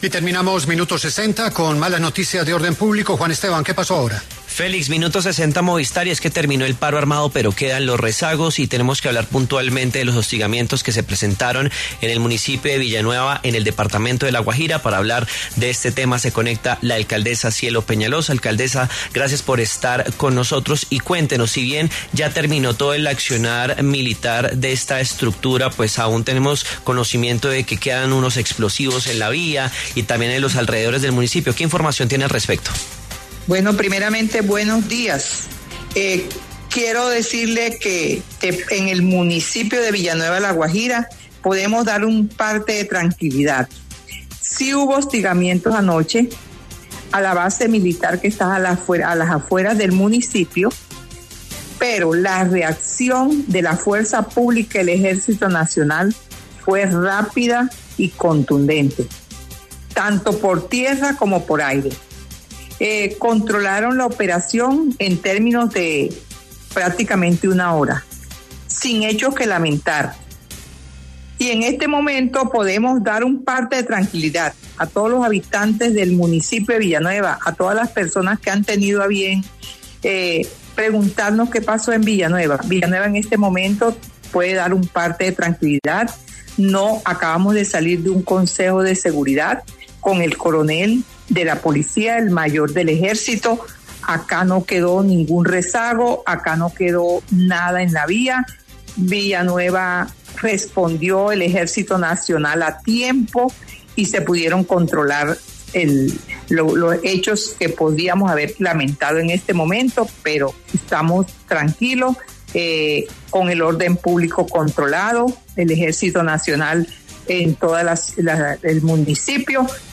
Cielo Peñalosa, alcaldesa de Villanueva, La Guajira, conversó con Julio Sánchez Cristo para La W acerca del hostigamiento provocado por integrantes del Ejército de Liberación Nacional (ELN) contra una base militar ubicada en este municipio.